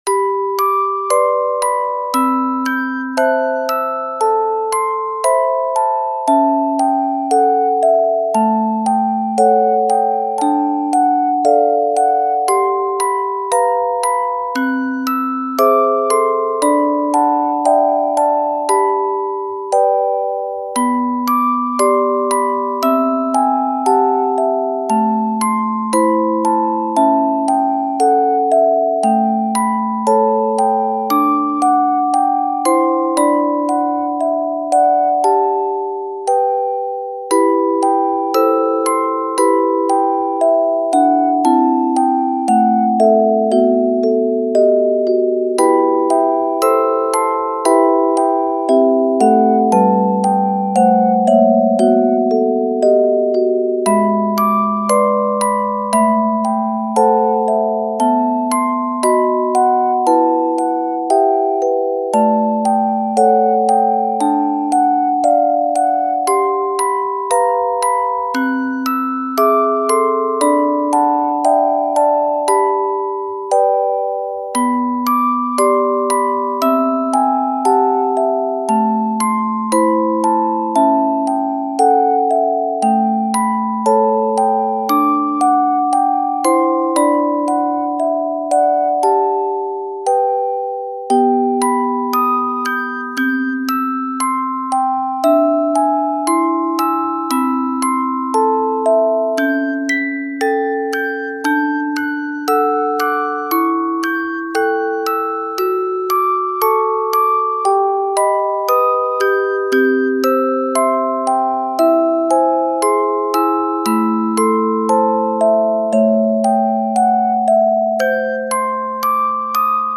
♪本物のオルゴールからサンプリングしたリアルな音色を使用。